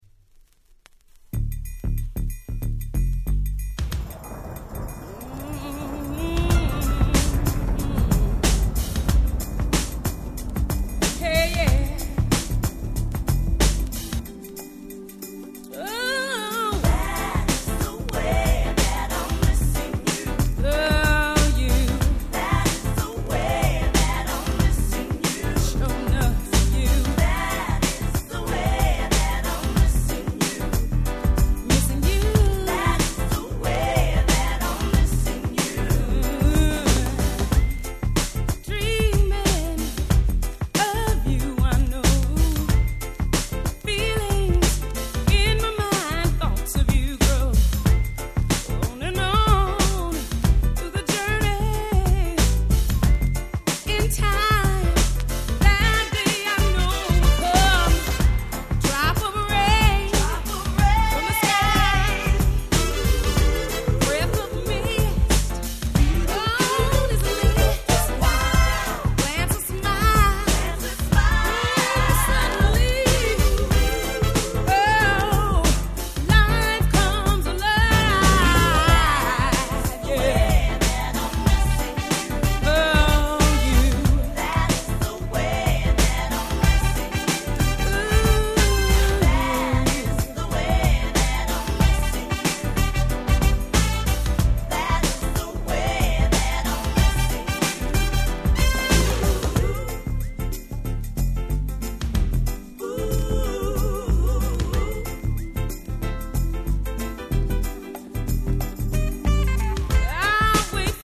UK R&B Classic !!